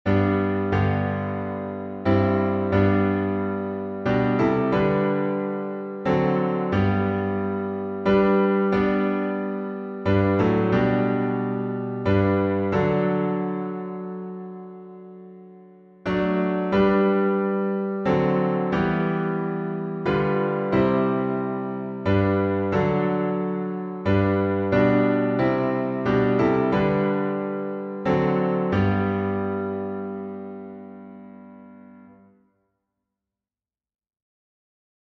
#2026: Alas! and Did My Savior Bleed — G major with five stanzas — Martyrdom | Mobile Hymns
Key signature: A flat major (4 flats) Time signature: 3/4 Meter: 8.6.8.6.(C.M.)